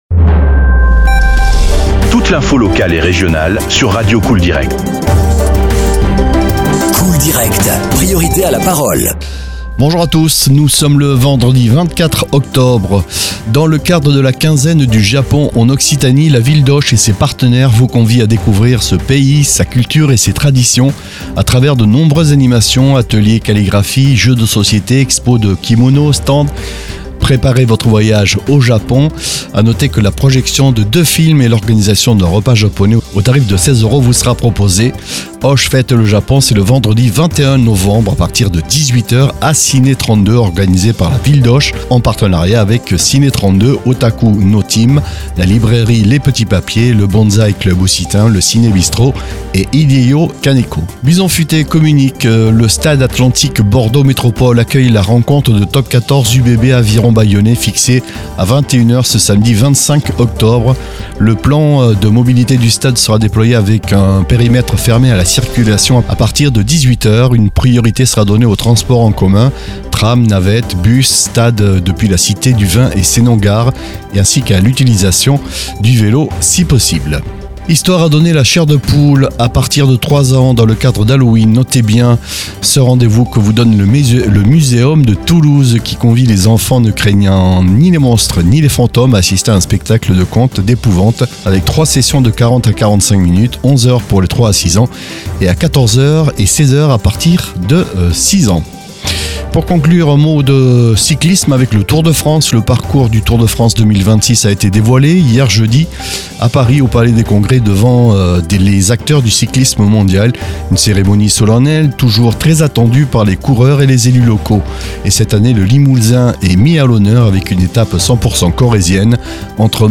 Flash infos 24/10/2024